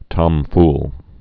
(tŏmfl)